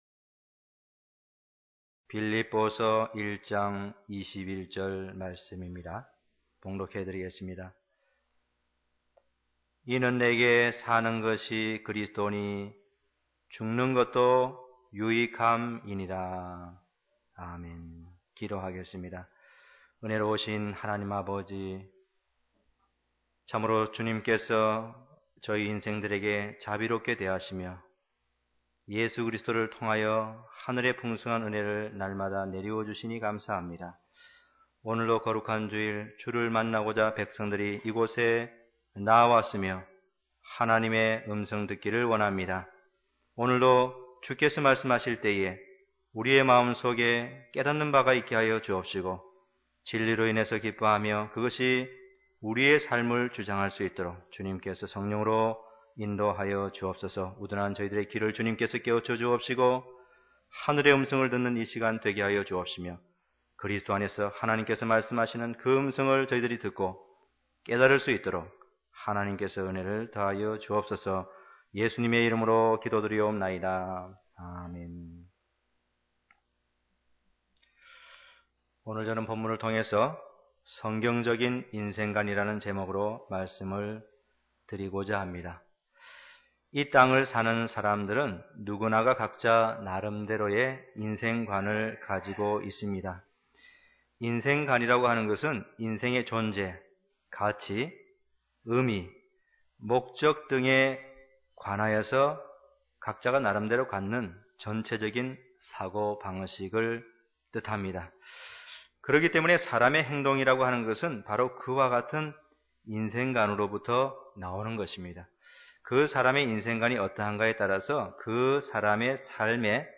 단편설교